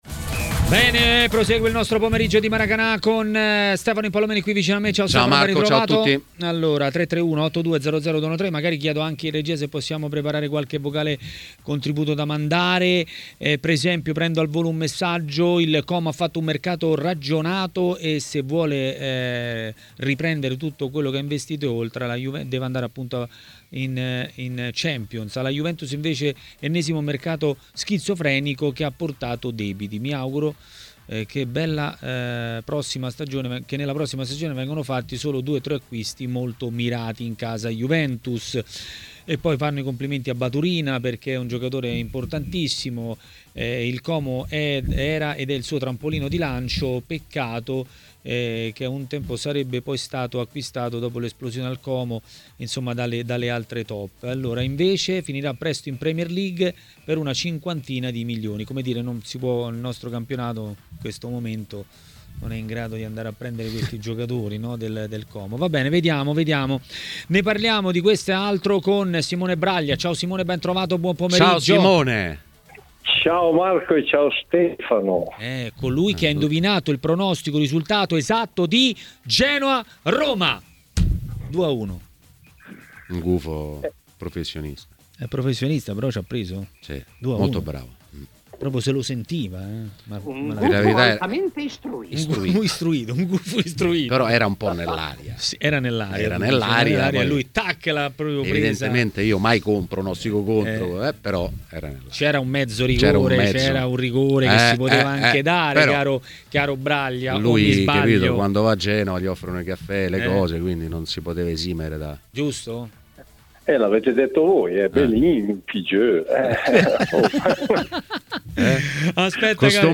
A parlare di derby e non solo a TMW Radio, durante Maracanà, è stato l'ex calciatore Antonio Paganin.